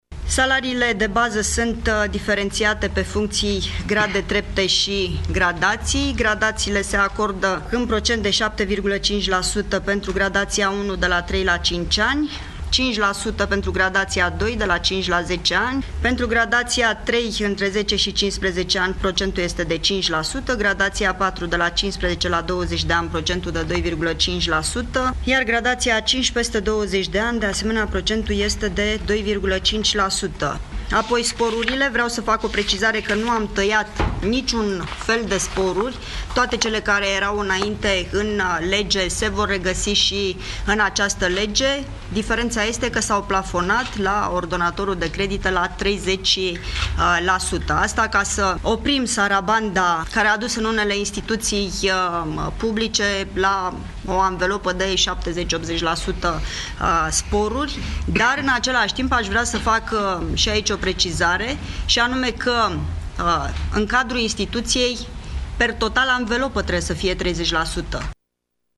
La 1 iulie 2017 soldele de funcţie ale personalului militar vor fi majorate cu 20% faţă de martie, iar polițiștii vor avea salarii cu 5% mai mari, a spus ministrul Muncii Lia Olguța Vasilecu, care a precizat că sporurile au rămas însă plafonate la 30%.